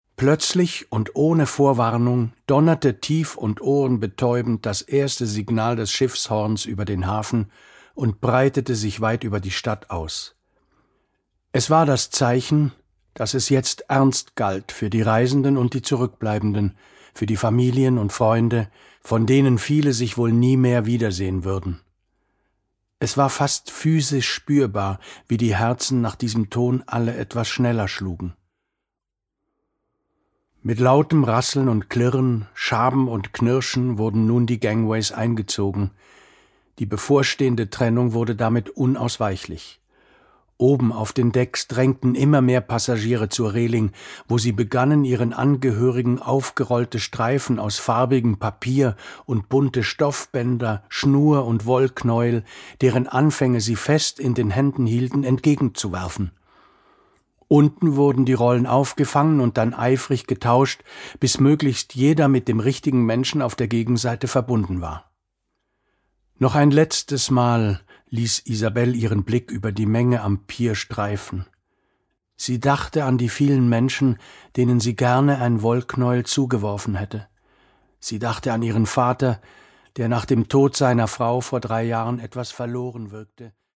Hörbuch
Verschaffe Dir einen Eindruck durch einige Ausschnitte des Hörbuchs...